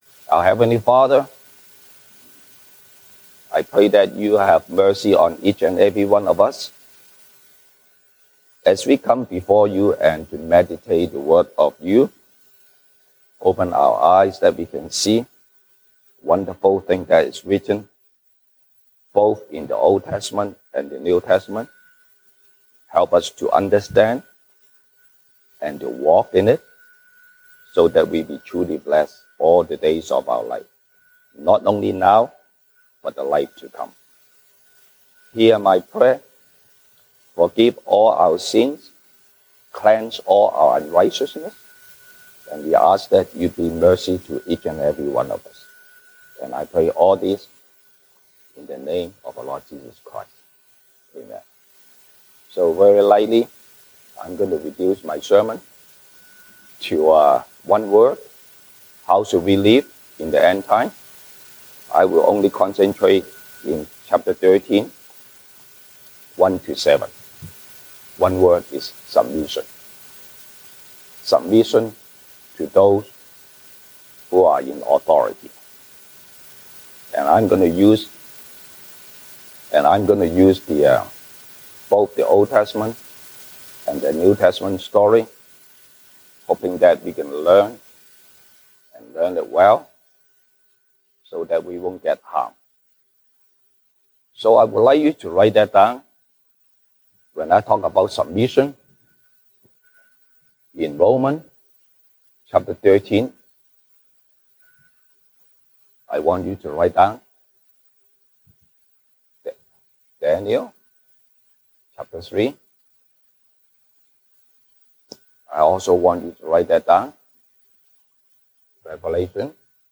西堂證道 (英語) Sunday Service English: How should we live in the end time?